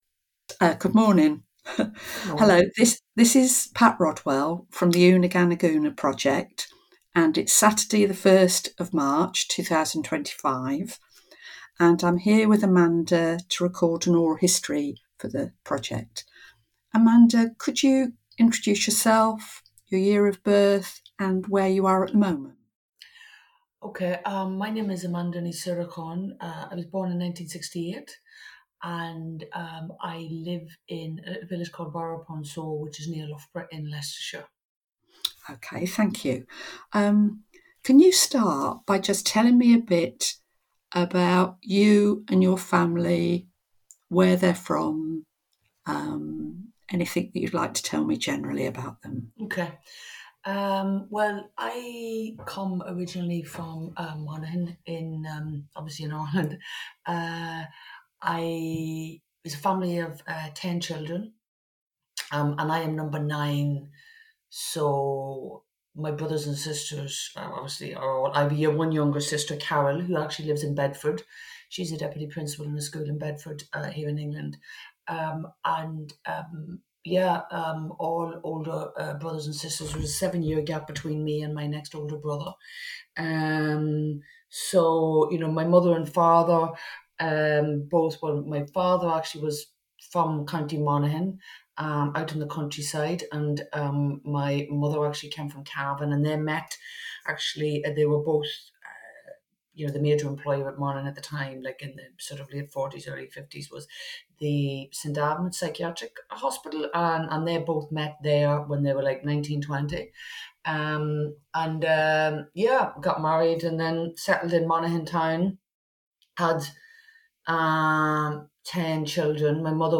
recorded online
Interview